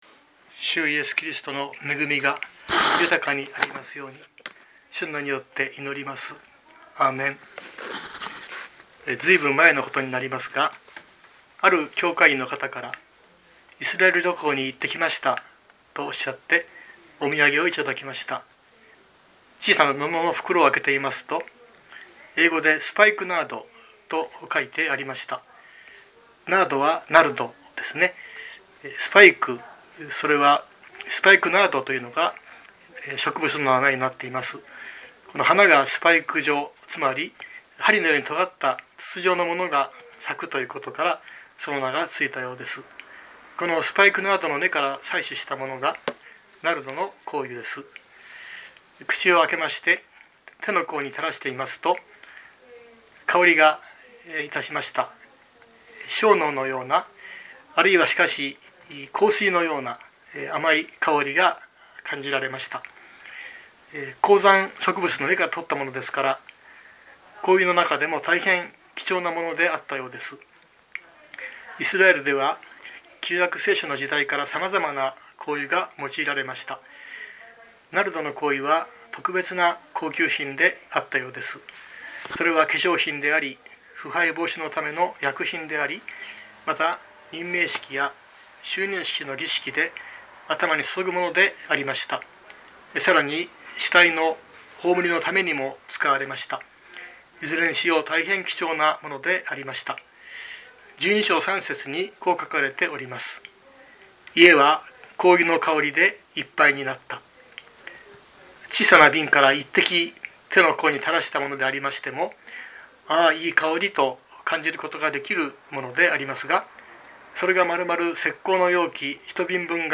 2023年06月04日朝の礼拝「イエス、香油を注がれる」熊本教会
熊本教会。説教アーカイブ。